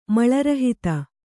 ♪ maḷa rahita